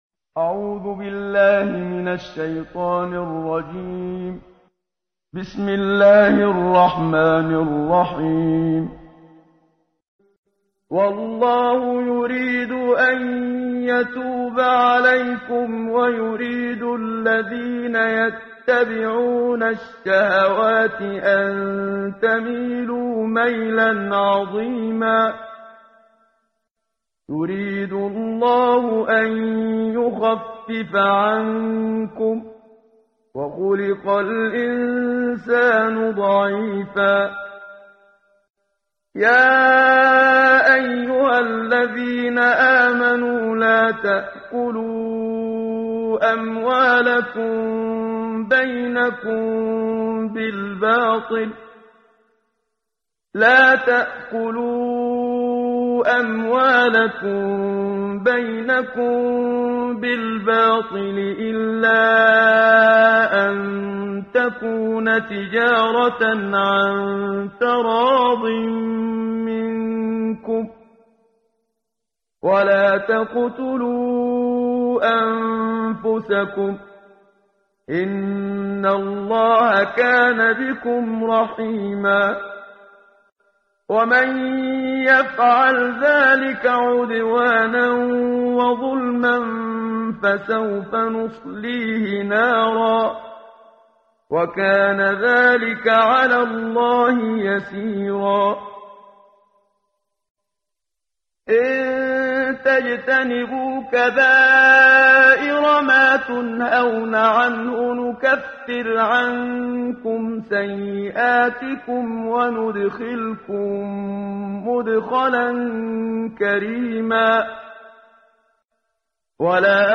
قرائت قرآن کریم ، صفحه 83، سوره مبارکه نساء آیه 27 تا 33 با صدای استاد صدیق منشاوی.